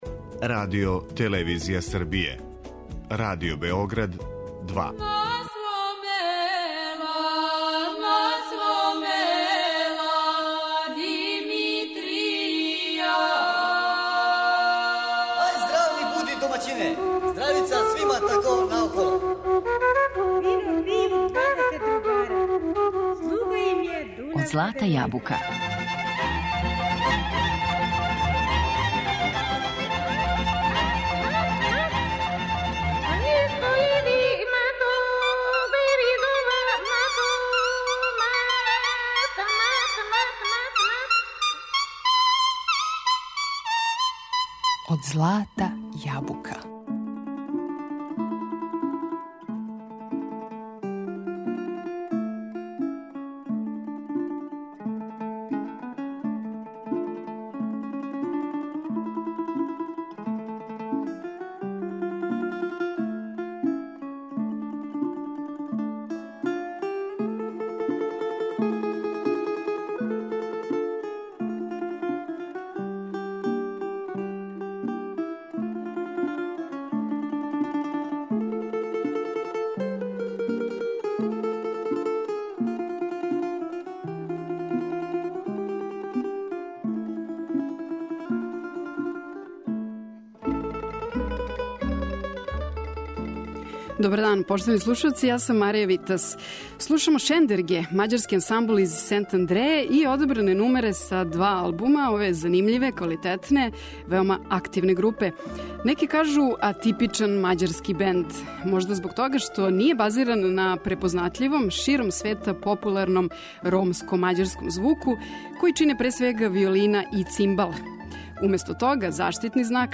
Овај, како кажу, атипичан мађарски бенд, свој звук не базира на препознатљивом, широм света популарном ромско-мађарском наслеђу које, пре свега, граде виолина и цимбал. Уместо тога, заштитни знак "Шендергеа" је тамбура - инструмент традиционално негован међу Србима и Хрватима у Мађарској.